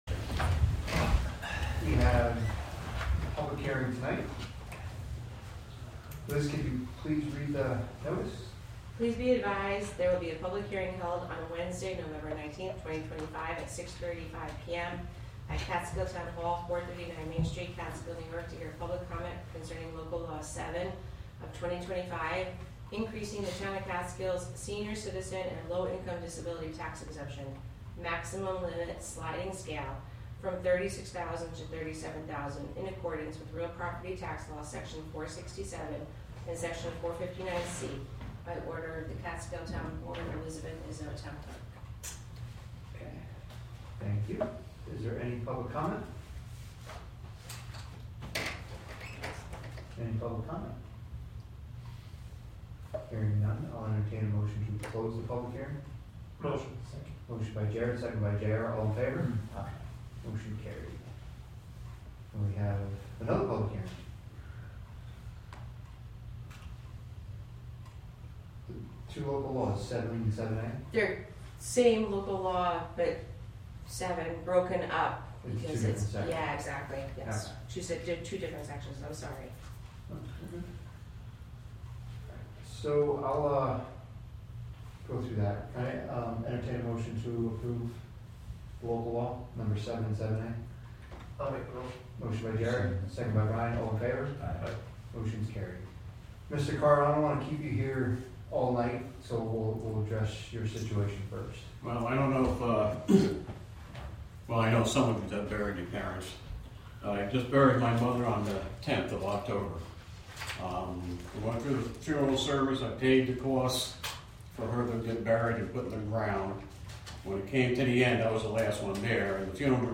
Live from the Town of Catskill: November 19, 2025 Catskill Town Board Meeting Public Hearing w (Audio)